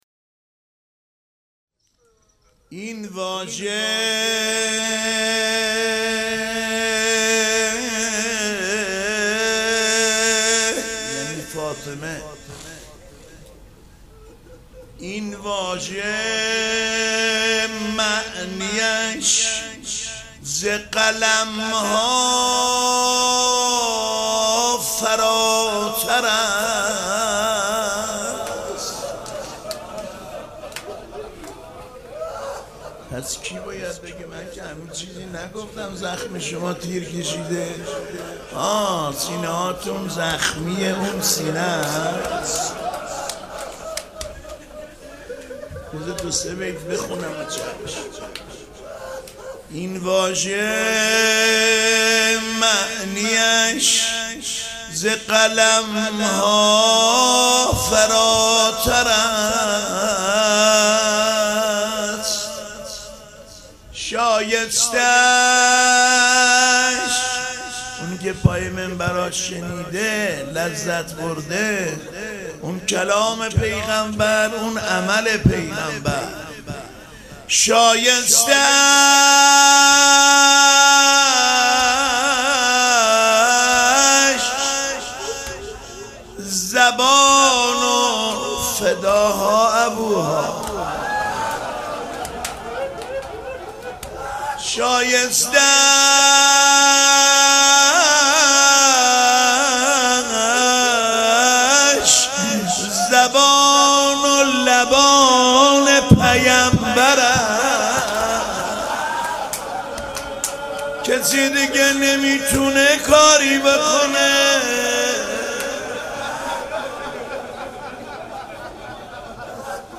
حسینیه ریحانة‌الحسین (س)
مدح
مداح
استاد حاج علی انسانی